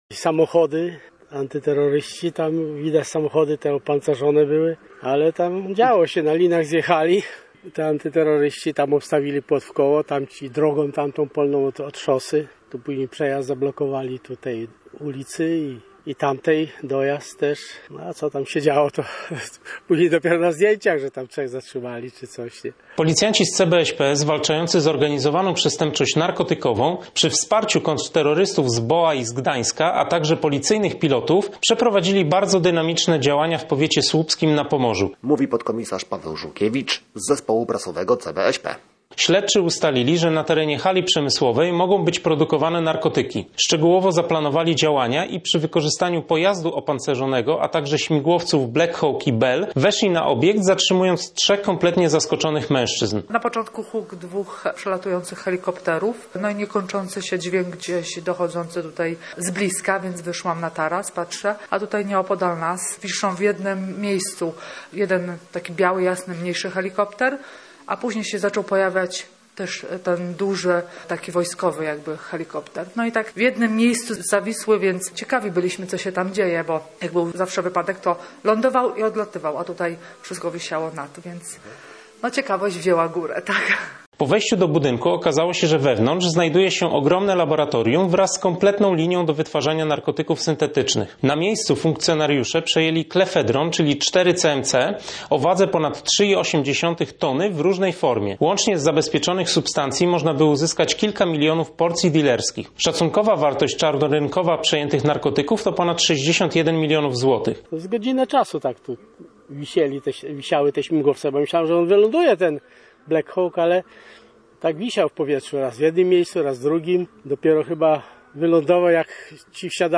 Tak niedzielne wydarzenia relacjonują mieszkańcy Potęgowa: